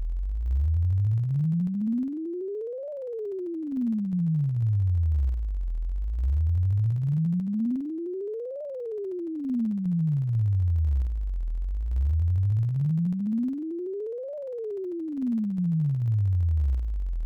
GenerateSweepWaveFile